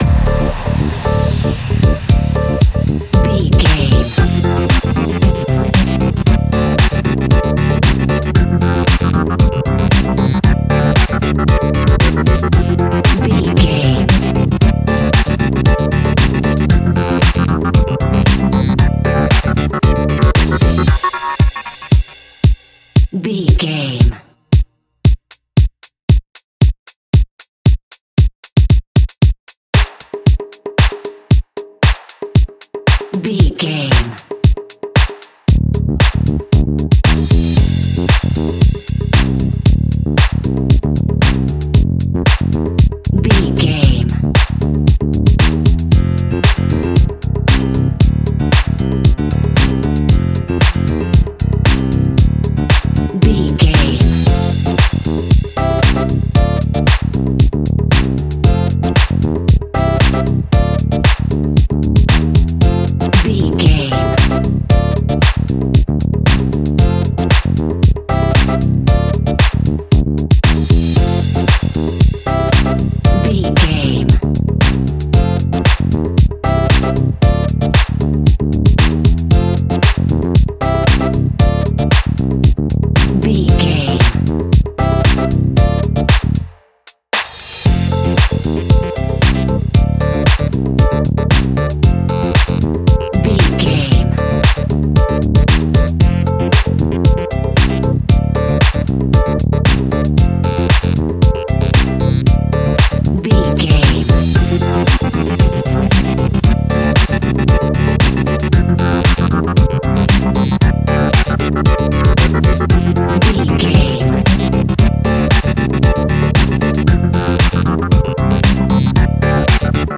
Funky House Kandi Music Theme.
Aeolian/Minor
intense
energetic
repetitive
electric guitar
bass guitar
synthesiser
drum machine
piano
funky house
funky synths
percussion